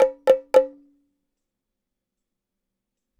BongoTriplet.wav